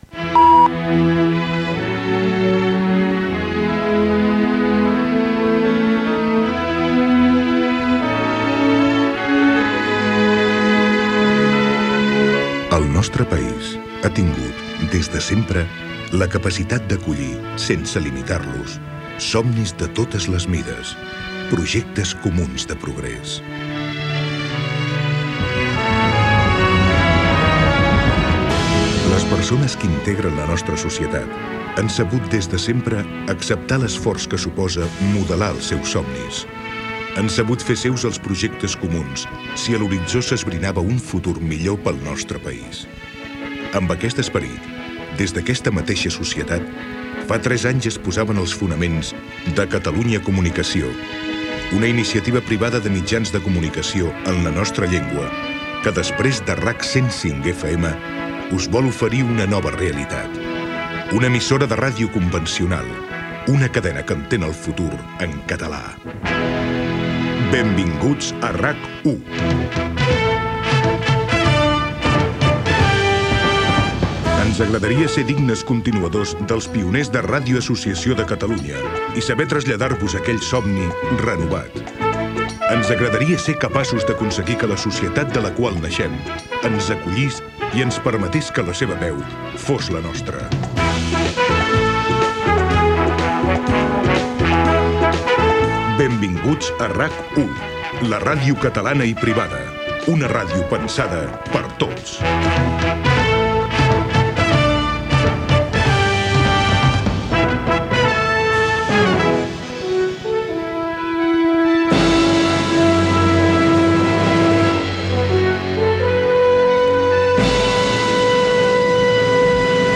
d3e86fa2708dd4ba0e8cc5026b80b6a3326de9ea.mp3 Títol RAC 1 Emissora RAC 1 Barcelona Cadena RAC Titularitat Privada nacional Descripció Editorial de Catalunya Comunicació, salutació, presentació de la programació.
Entrevista a Joaquim Nadal, alcalde de Girona.